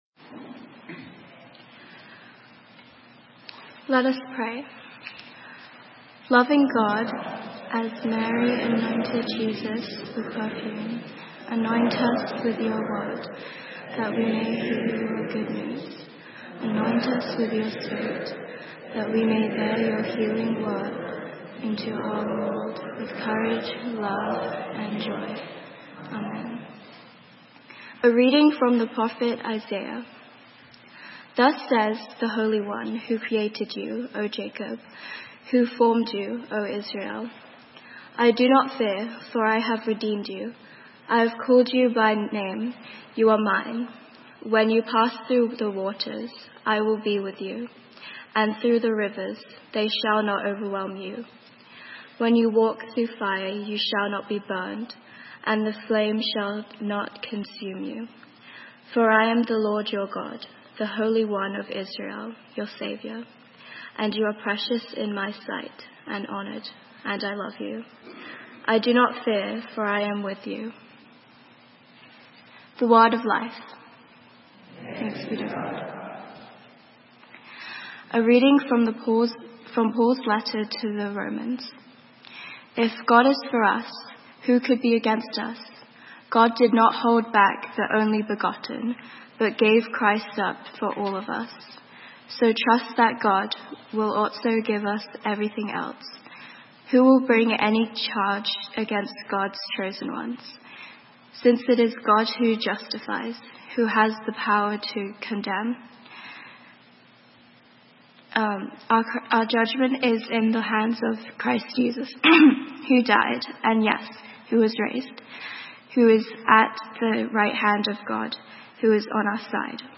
Sermon:Love's Anointing - St. Matthew's UMC